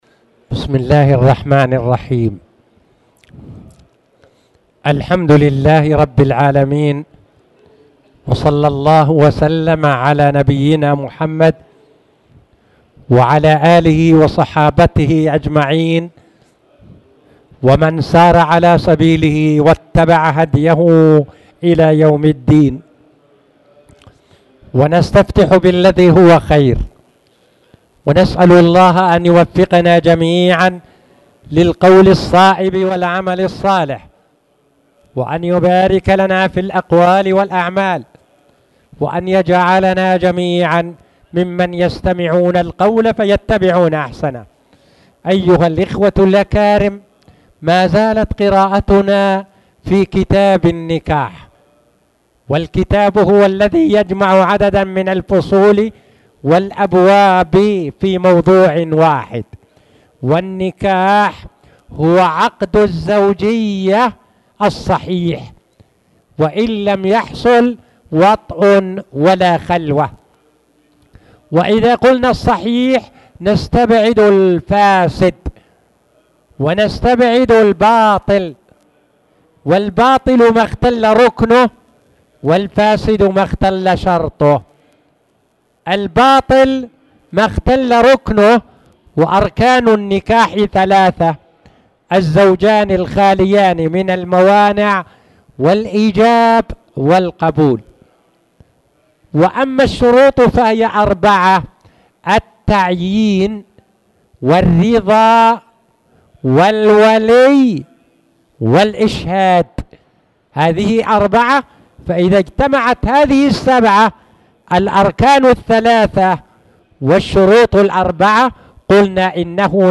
تاريخ النشر ٢٨ ربيع الأول ١٤٣٨ هـ المكان: المسجد الحرام الشيخ